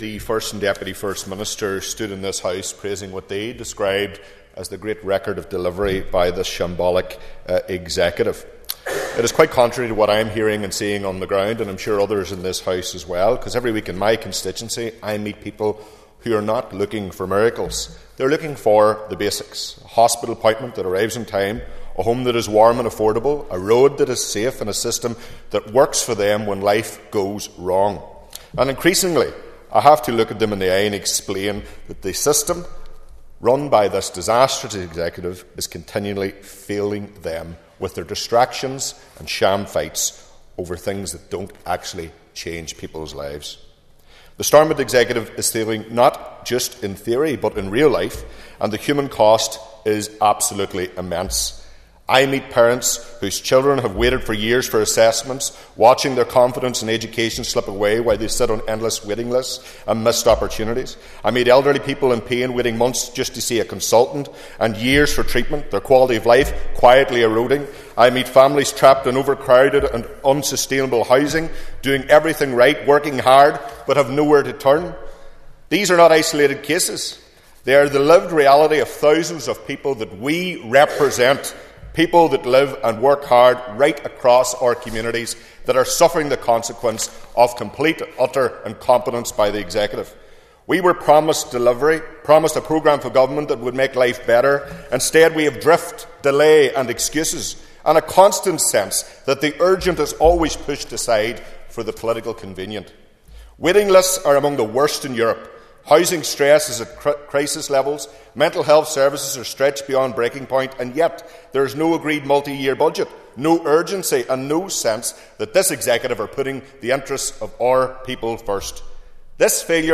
That’s the belief of West Tyrone MLA Daniel McCrossan, who told the Assembly this week that while people are facing delays in the health service, lack of adequate housing, a cost of living crisis, and other real challenges, the focus of the Executive seems to be on political point scoring.
You can listen to Mr McCrossan’s full speech here –